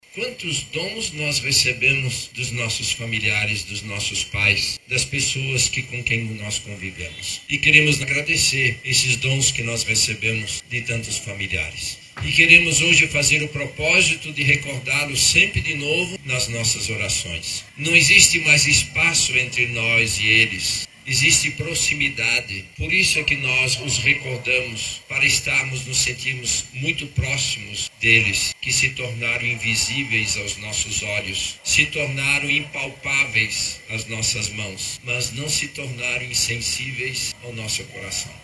Em sua homilia, o cardeal Leonardo Steiner reforçou o sentido espiritual da celebração. Ele destacou que recordar os falecidos não é apenas um gesto de saudade, mas também um ato de amor e fé, que nutre a certeza da vida eterna.